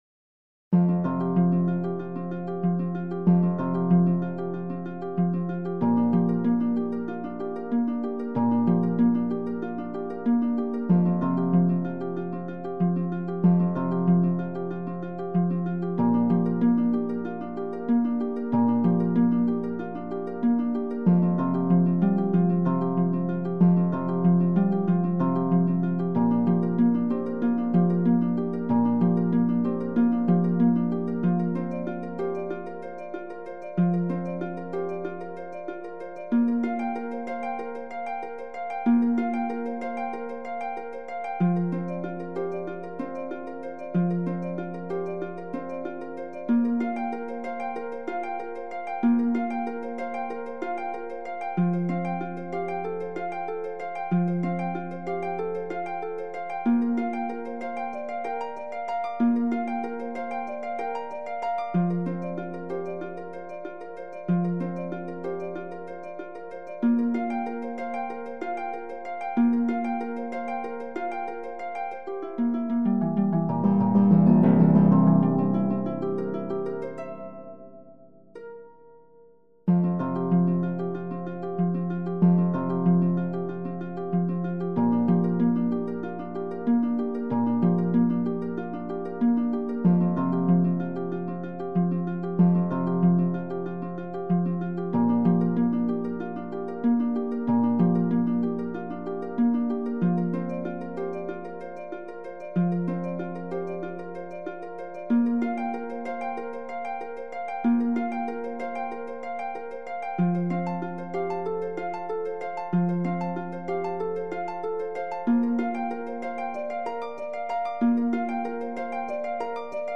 Original Music
ハープ